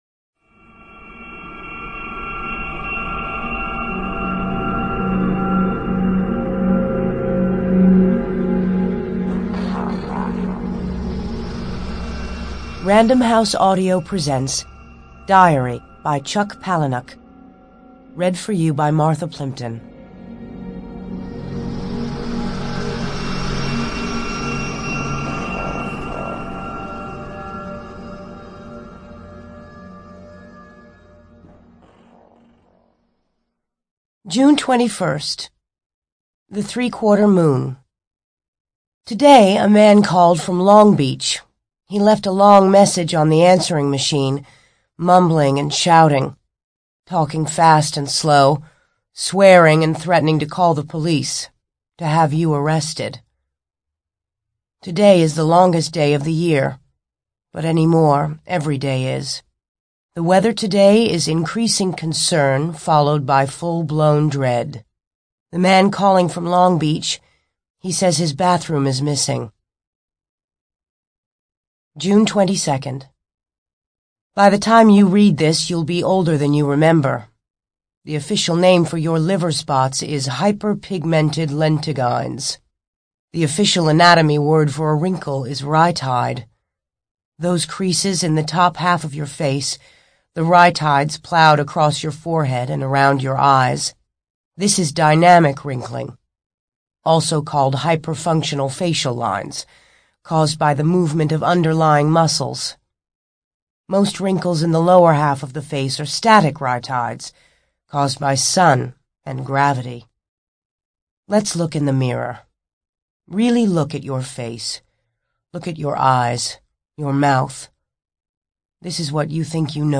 ЖанрКниги на языках народов Мира